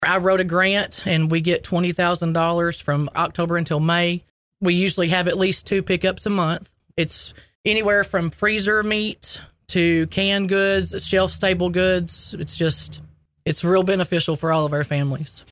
talked to ECNN about Mammoth Spring School Food Pantry